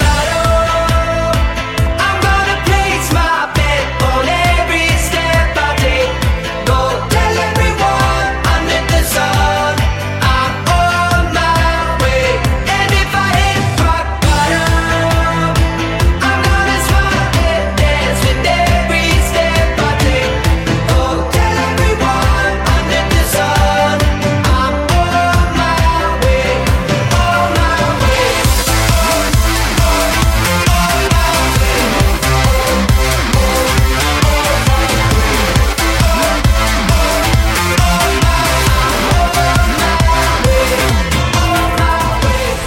club